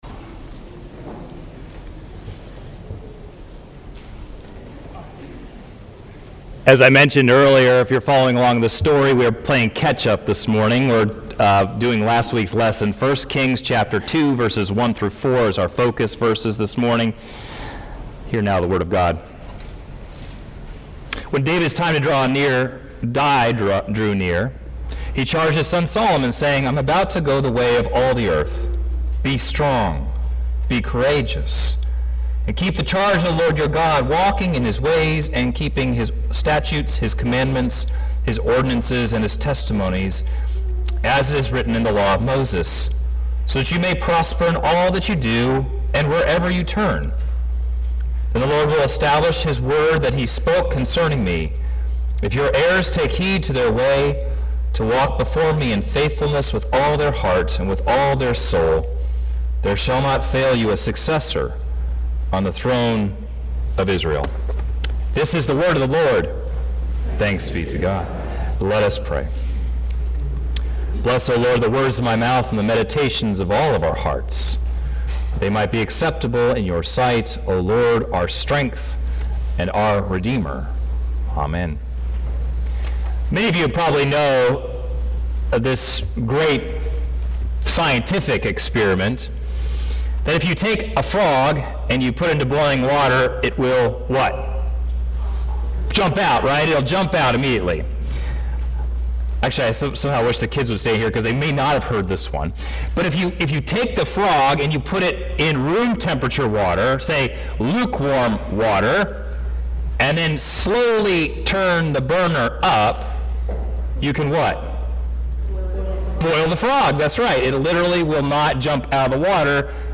Sermon-1.15.17.mp3